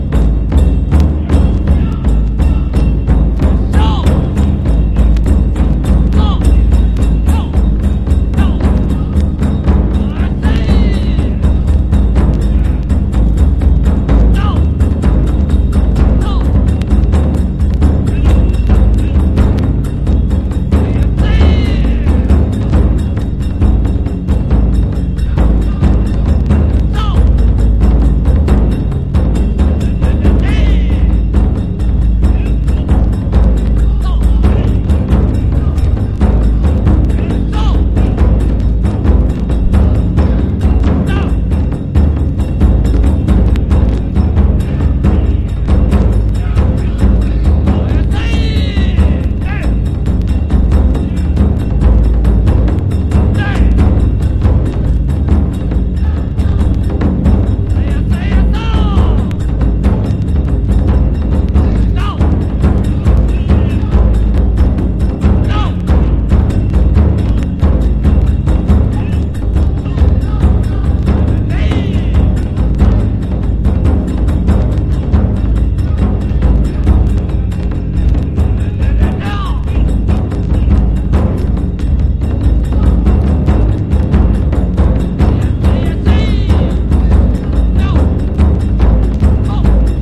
他にも尺八や三味線の独演も収録。
和モノブレイク / サンプリング